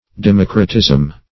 Democratism \De*moc"ra*tism\, n.